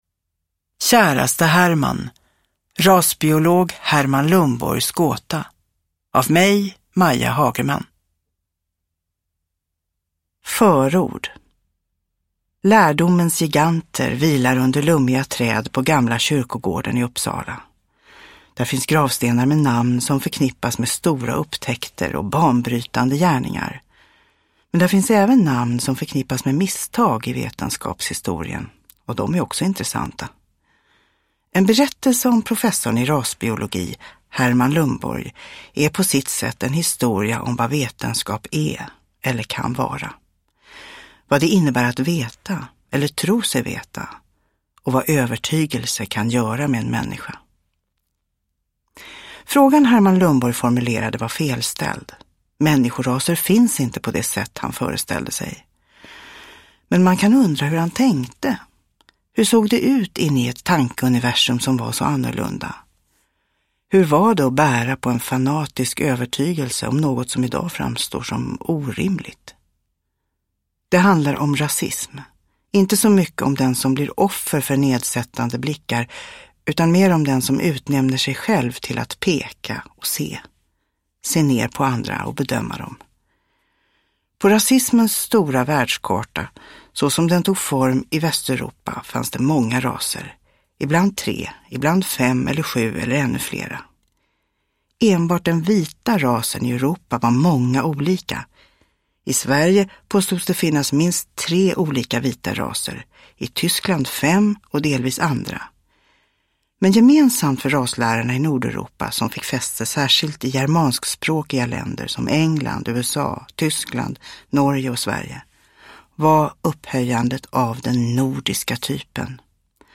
Käraste Herman : rasbiologen Herman Lundborgs gåta – Ljudbok – Laddas ner